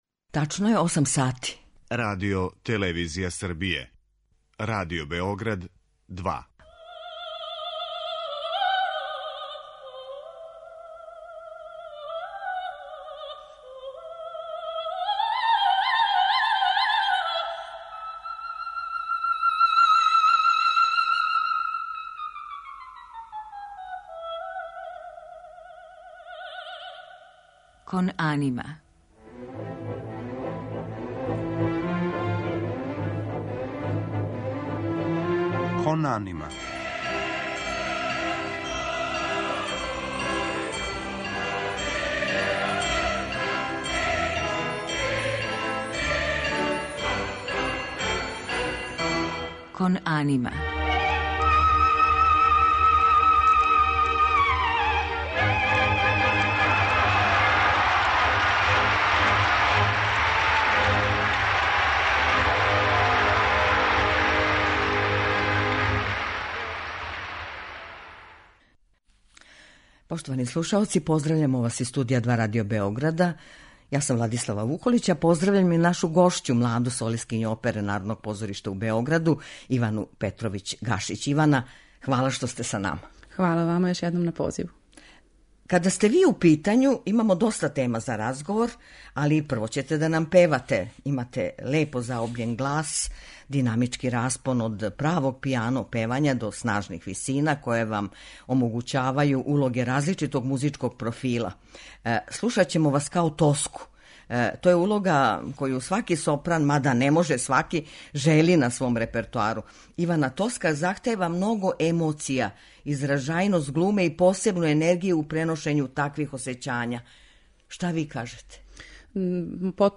У музичком делу емисије емитоваћемо фрагменте из опера Ђузепа Ведија и Ђакома Пучинија у извођењу ове младе а већ афирмисане уметнице.